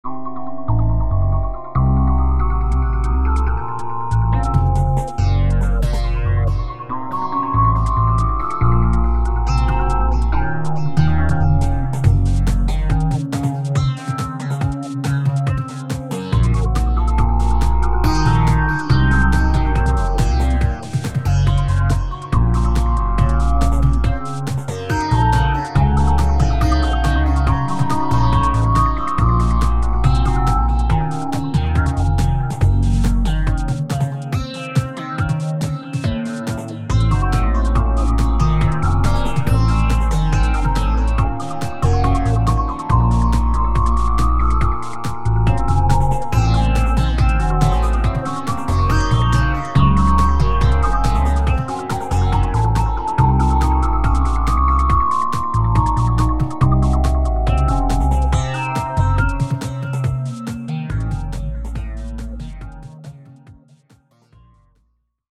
On obtient comme résultat une musique assez inhabituelle, structurée mais sans thème répétitif.
Ce sont 3 compositions "cousines".